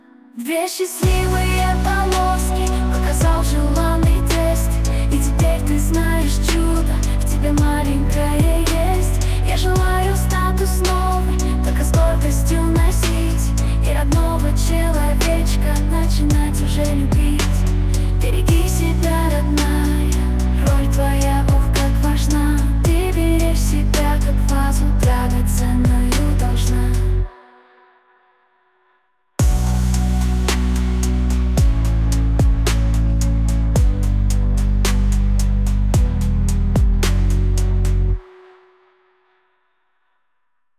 Мелодии на гендер пати, фоновая музыка, песни, демо записи: